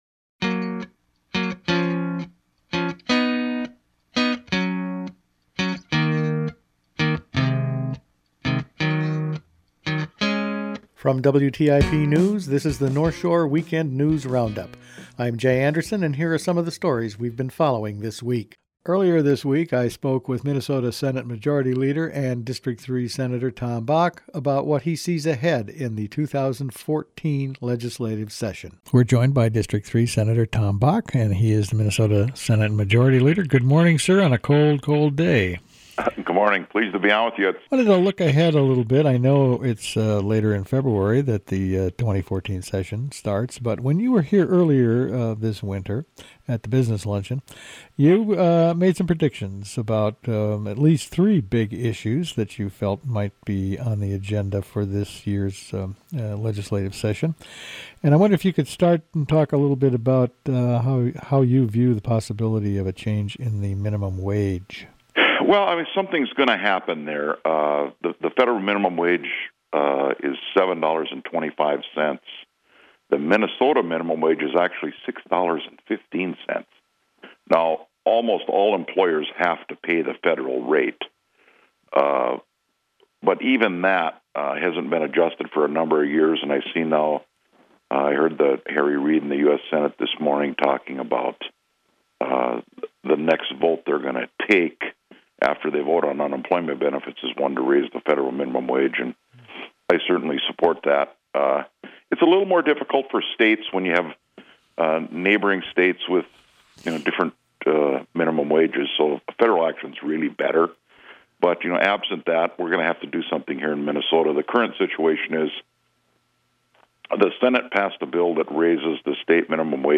Attachment Size FinalCut_010914.mp3 23.57 MB Each week the WTIP news staff puts together a roundup of the news over the past five days.